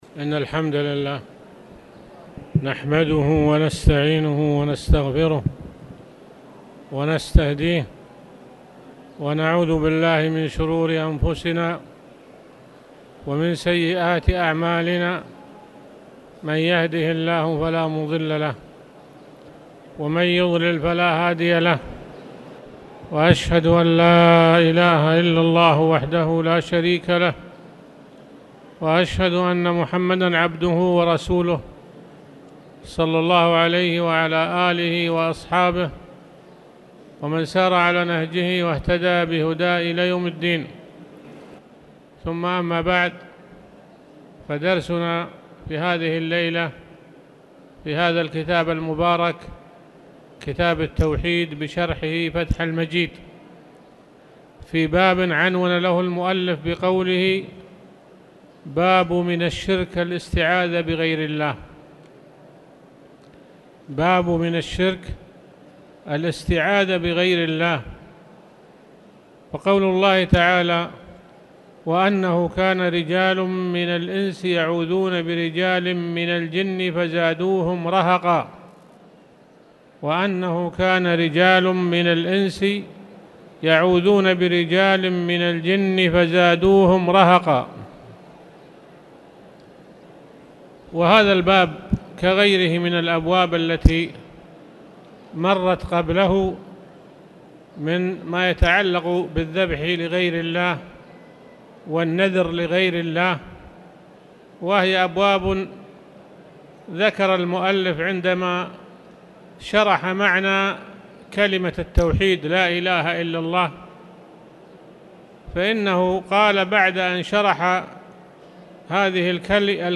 تاريخ النشر ٨ صفر ١٤٣٨ هـ المكان: المسجد الحرام الشيخ